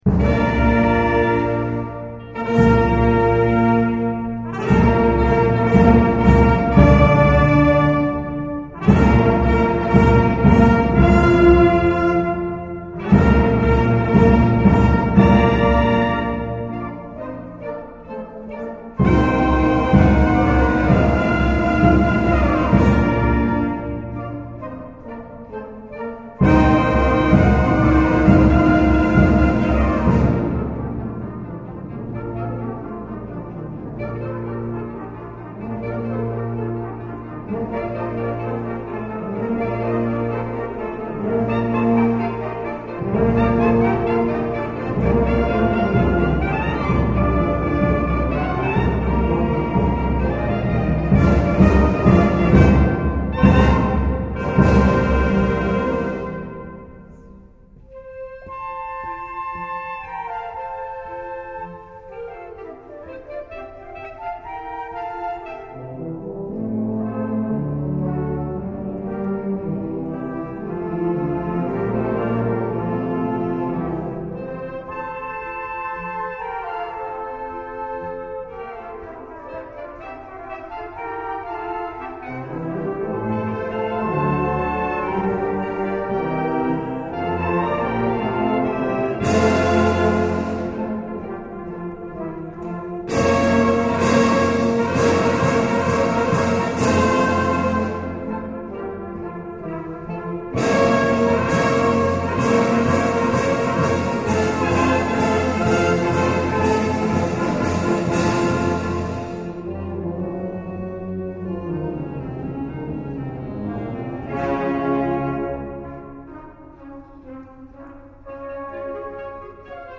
BANDA MUSICALE
Concerto di Natale 2010